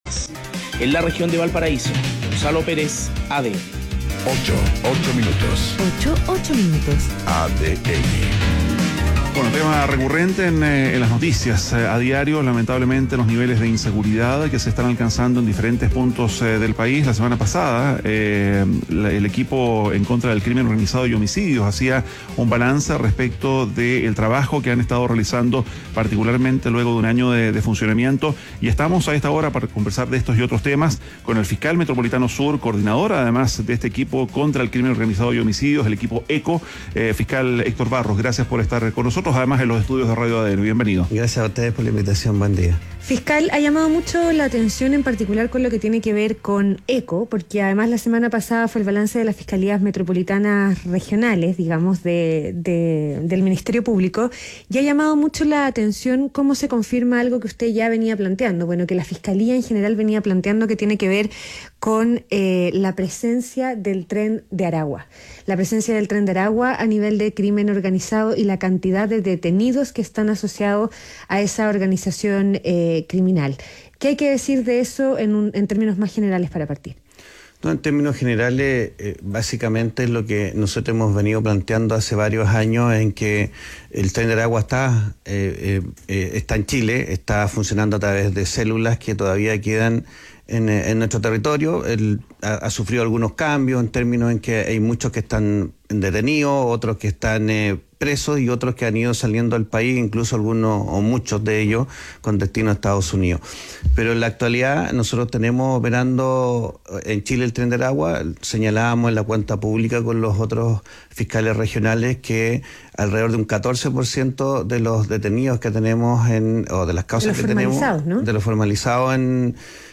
ADN Hoy - Entrevista a Héctor Barros, fiscal regional de la Fiscalía Metropolitana Sur y Coordinador el Equipo de Crimen Organizado y Homicidios (ECOH)